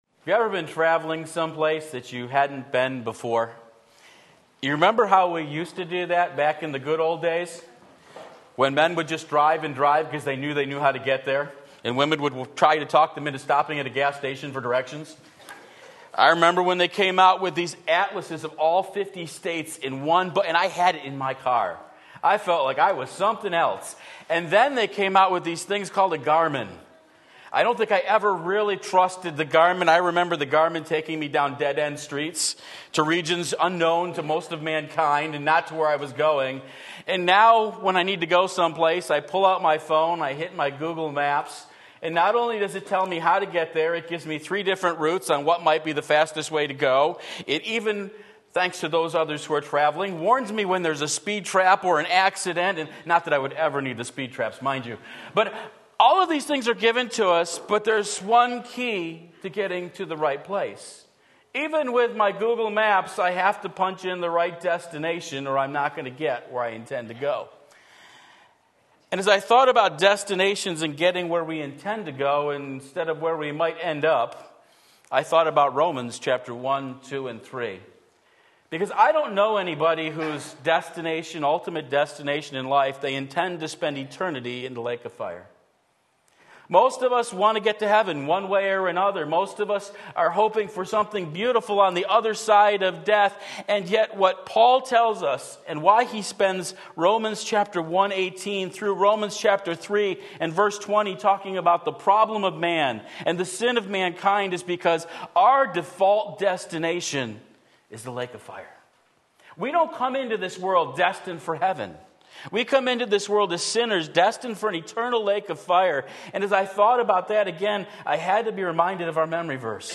Sermon Link
The Basis of Judgment Romans 2:12-29 Sunday Morning Service